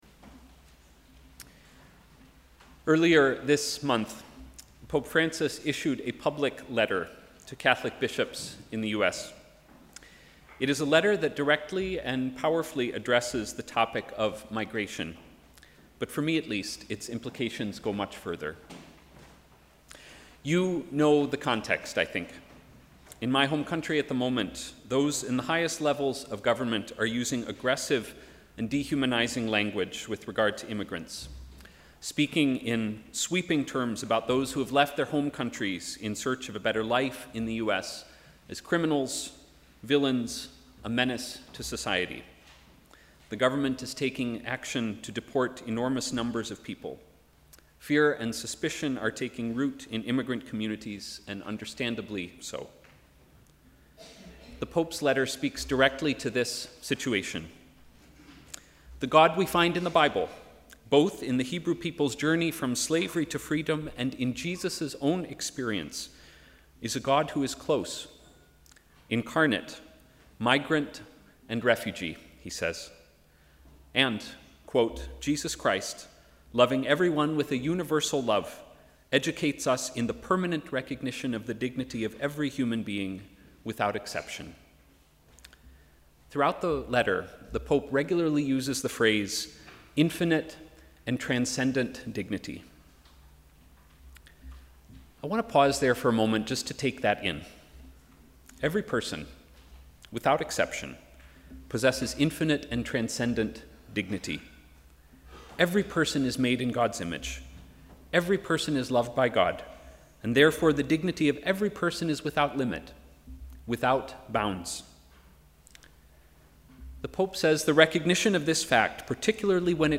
Sermon: ‘Love beyond limits’